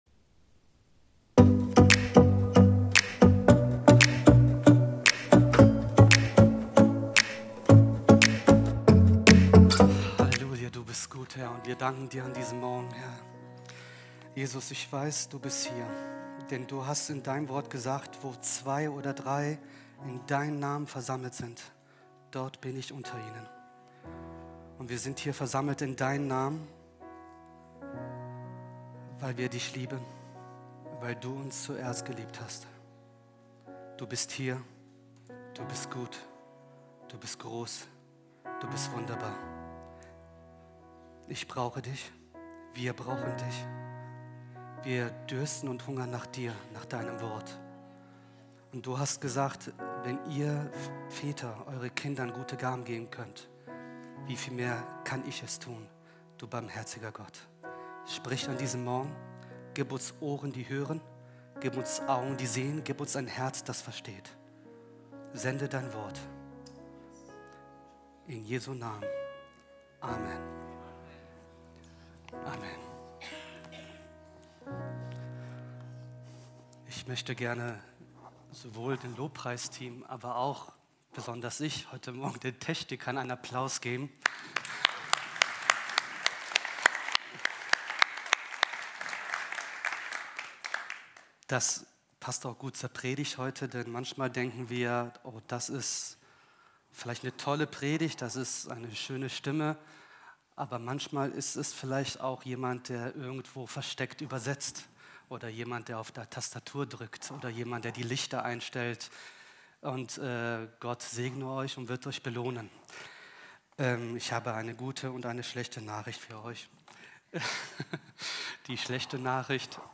Live-Gottesdienst aus der Life Kirche Langenfeld.
Kategorie: Sonntaggottesdienst